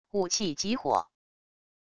武器及火wav音频